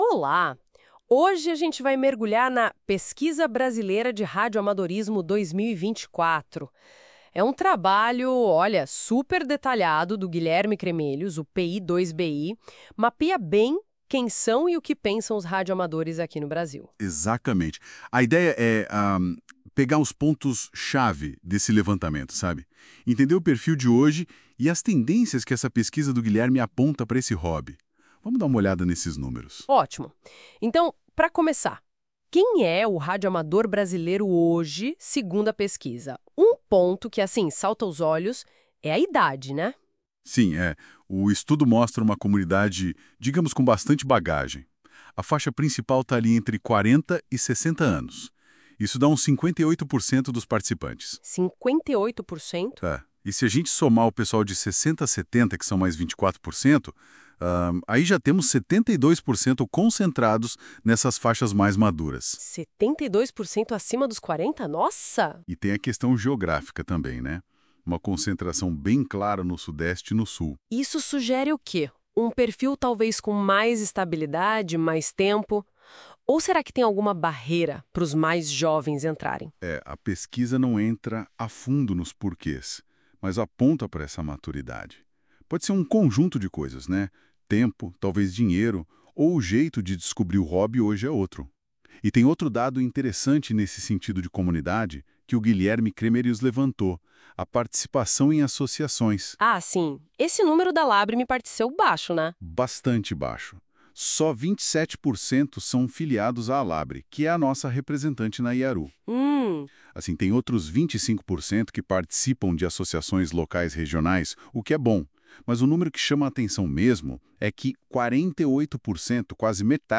Resumo em áudio da pesquisa, gerado por Inteligência Artificial, mas validado por um humano: